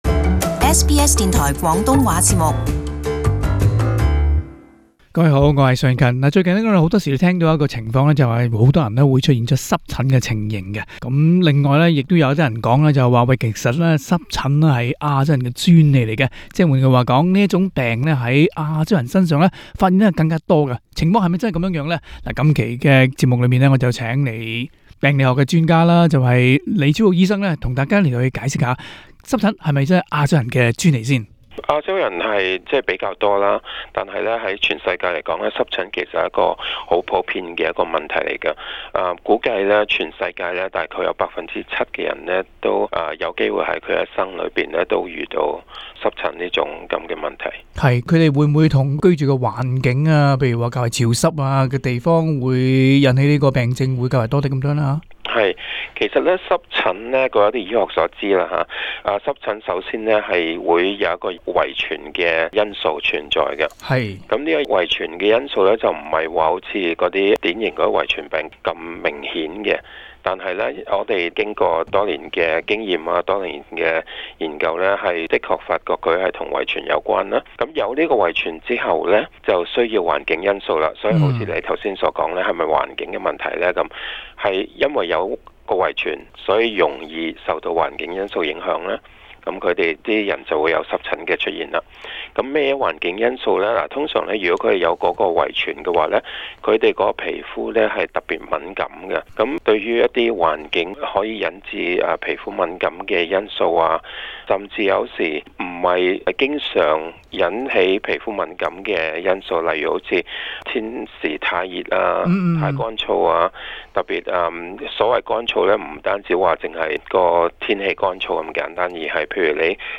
【社區專訪】難以根治的濕疹